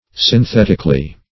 Synthetically \Syn*thet"ic*al*ly\, adv.